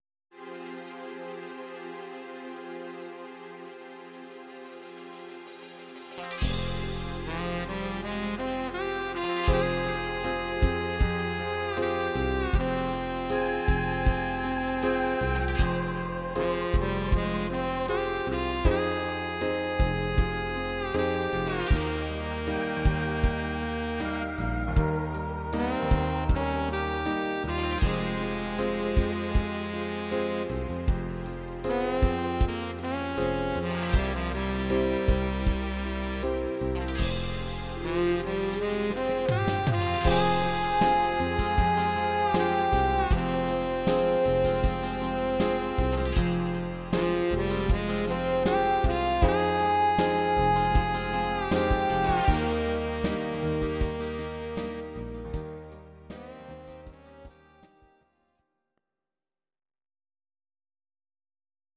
Audio Recordings based on Midi-files
Ital/French/Span, 1970s